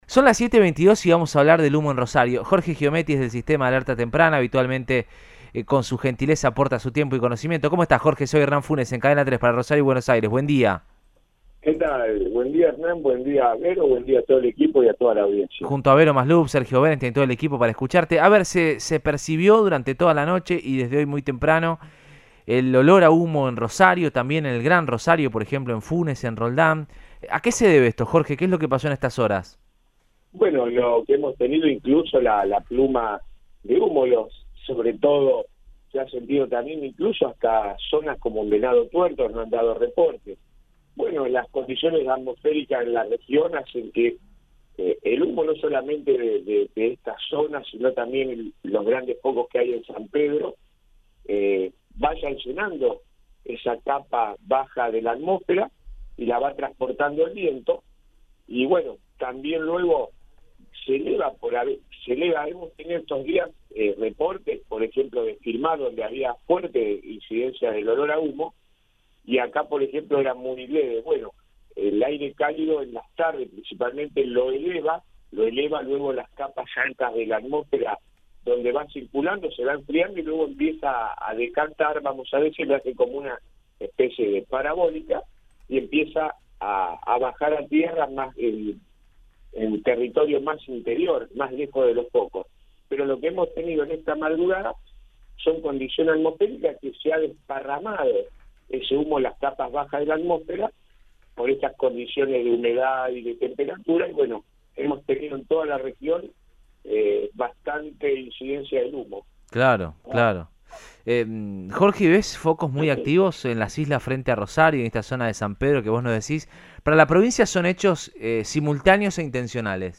habló en Radioinforme 3, por Cadena 3 Rosario